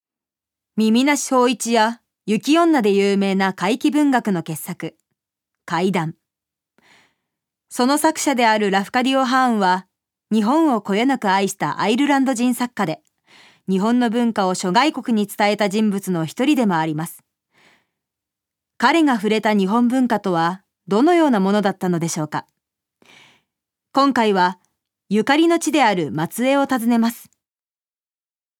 預かり：女性
ナレーション２